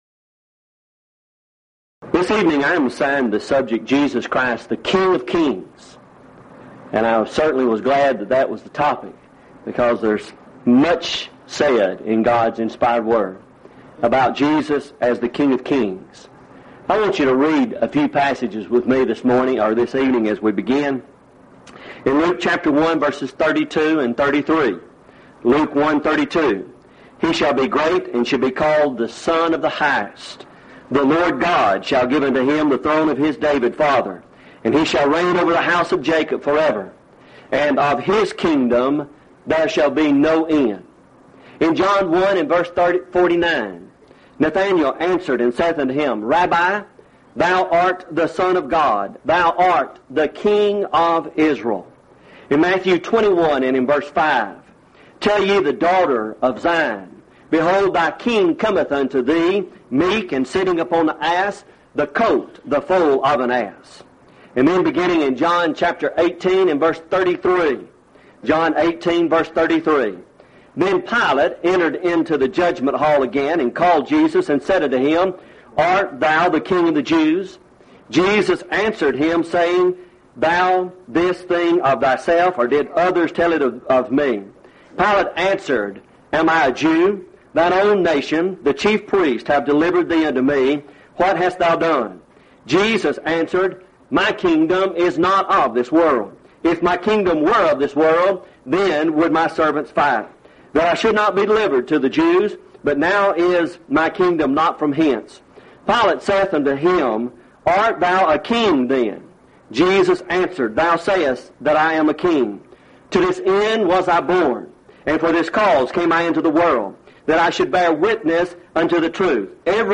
Event: 2nd Annual Lubbock Lectures
lecture